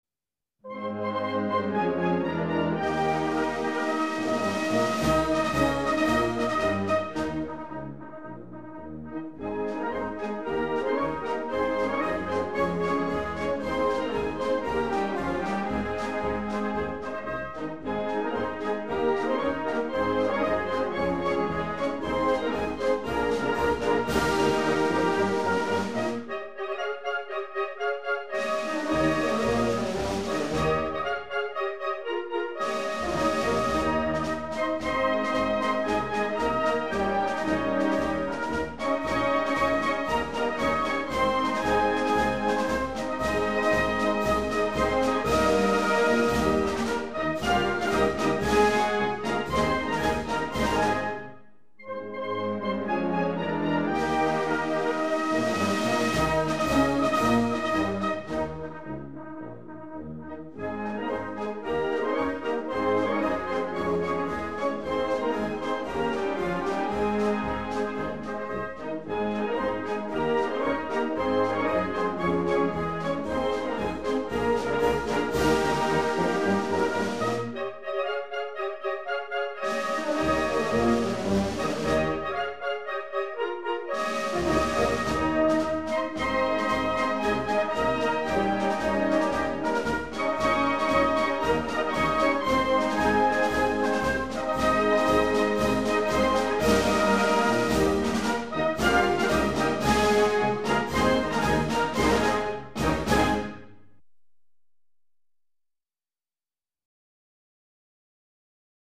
Versione musicale contemporanea
Marce Militari Italiane - Banda Arma Carabinieri - La leggenda del Piave.mp3